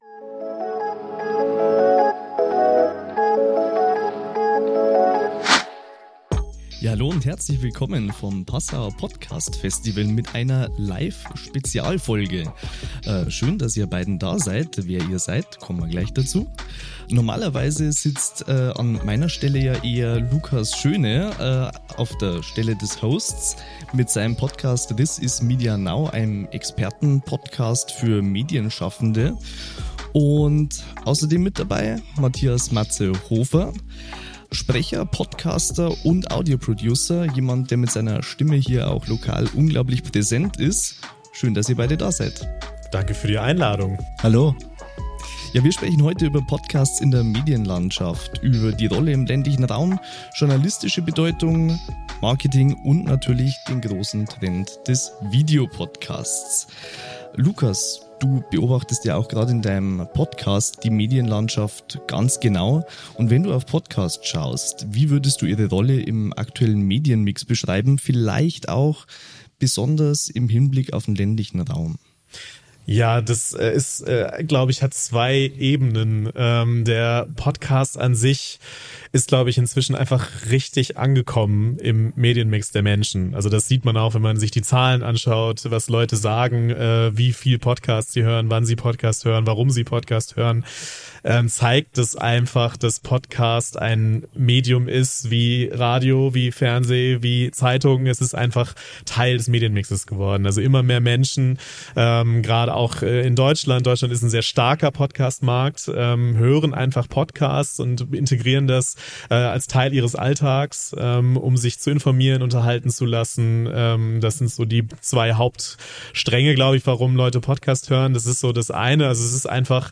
Das und viele weitere Einblicke in die Medienwelt erwartet Euch in dieser Live-Episode vom Passau–Podcast –Festival 2025.